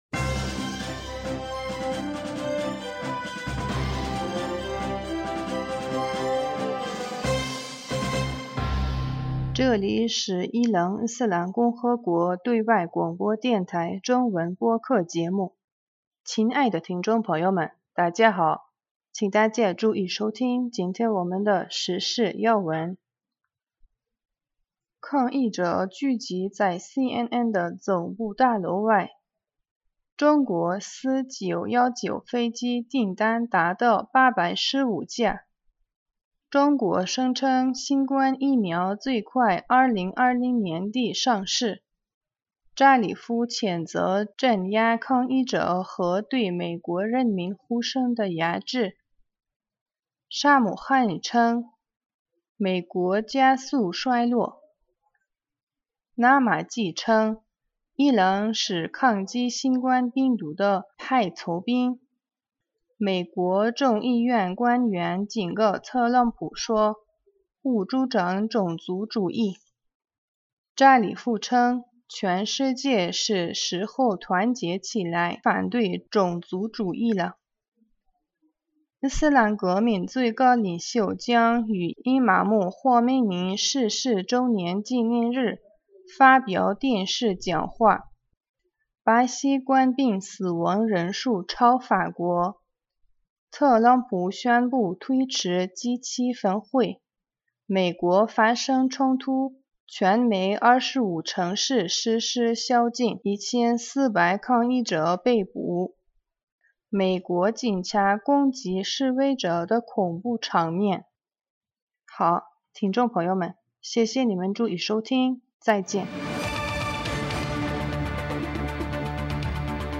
2020年5月31日 新闻